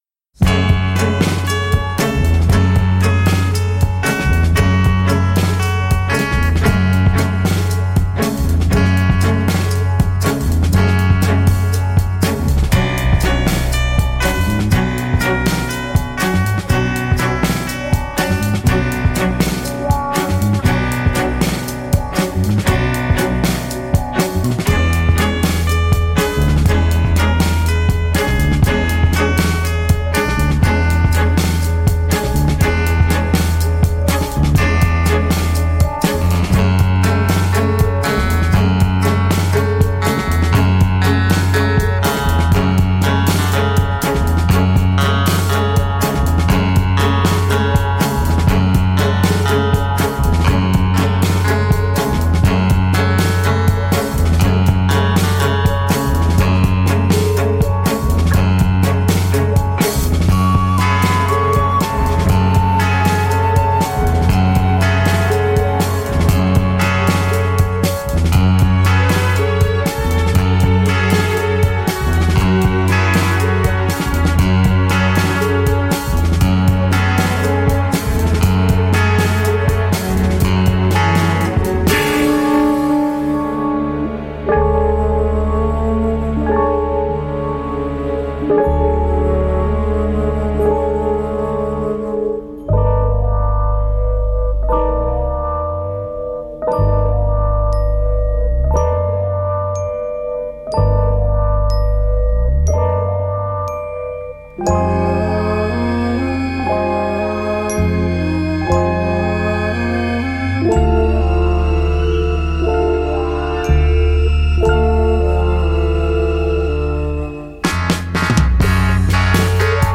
Virée dans le cosmos post-sixties.
Certes un peu répétitif, mais hypnotique.